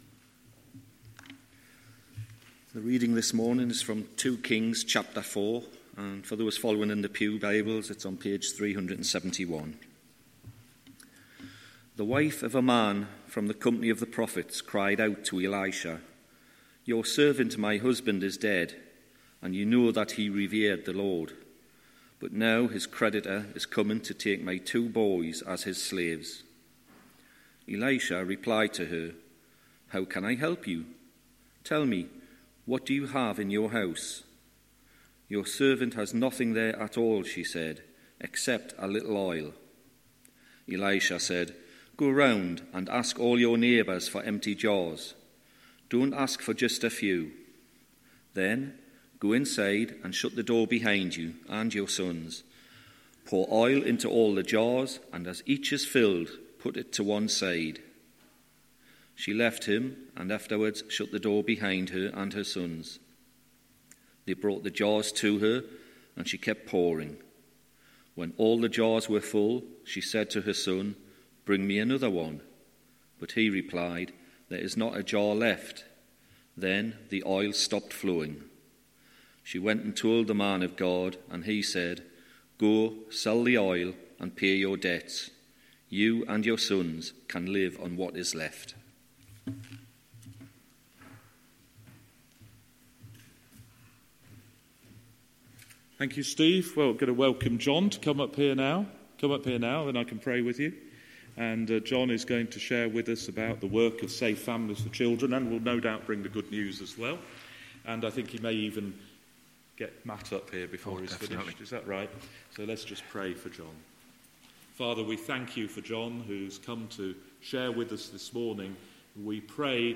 Morning Service , Guest Speaker